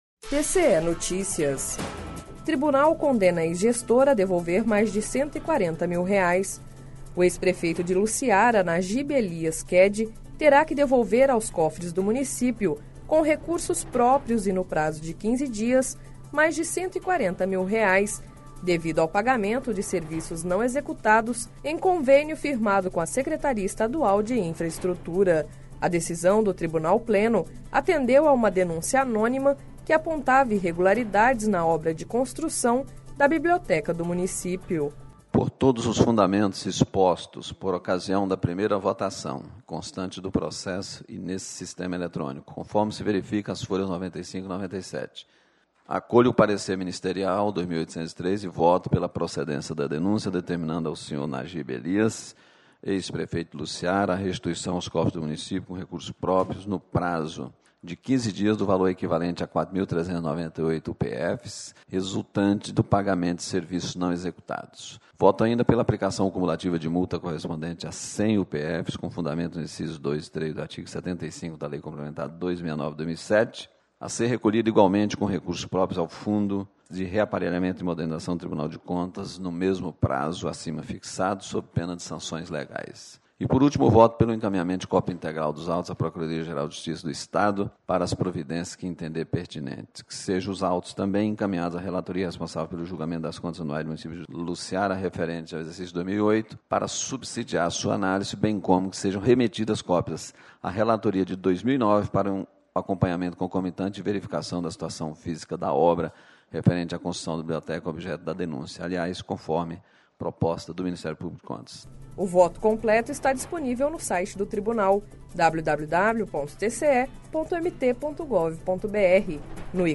Sonora: Valter Albano - conselheiro do TCE-MT